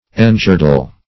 Engirdle \En*gir"dle\, v. t. To surround as with a girdle; to girdle.